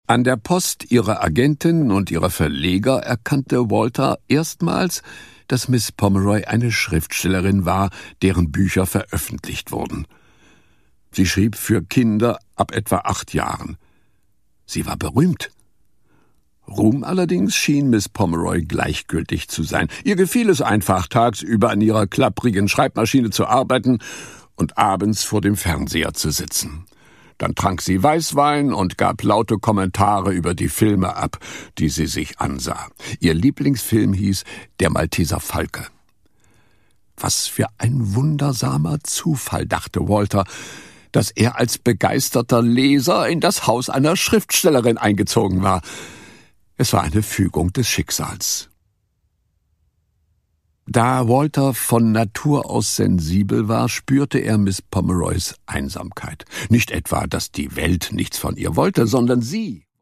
Produkttyp: Hörbuch-Download
Gelesen von: Jürgen Thormann